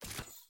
SD_SFX_GrapplingHook_Launch.wav